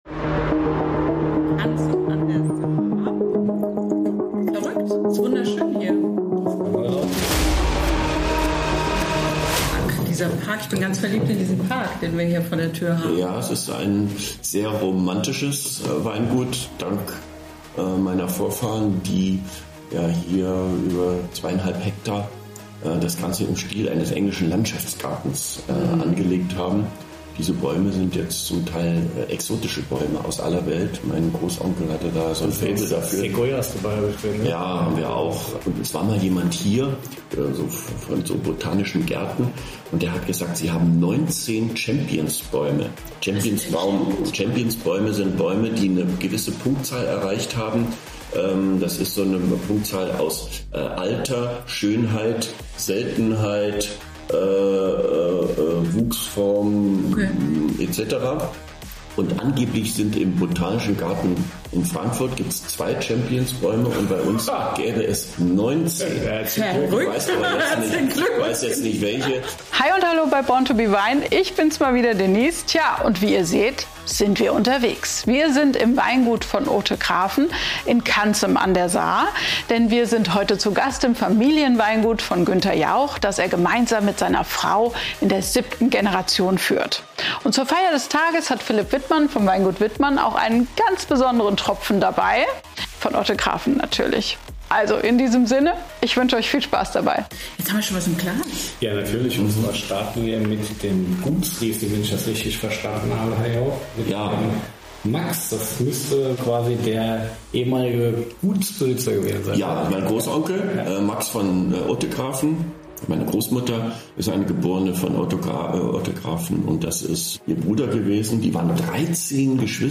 Oder, um präzise zu sein: BORN TO BE WINE ist zu Gast auf Weingut von Othegraven.